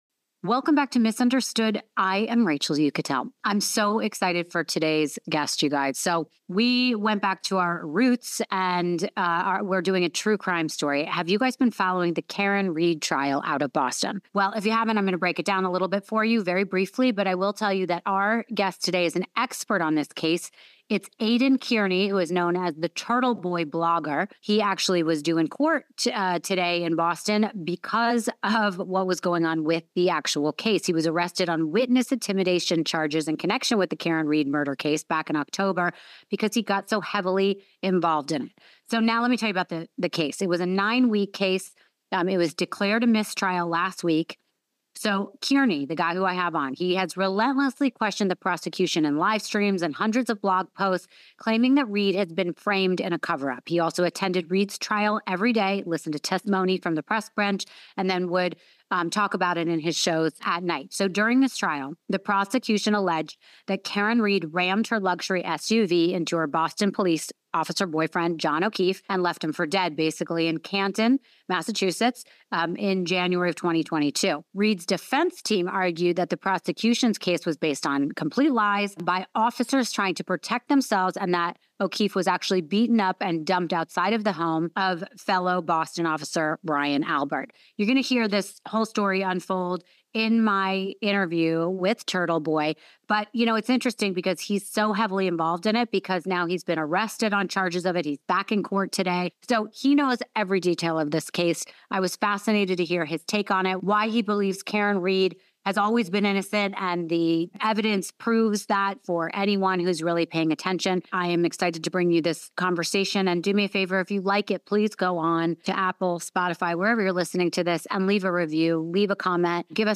He has become the go-to source of information on this case, and he doesn’t hold back in this interview!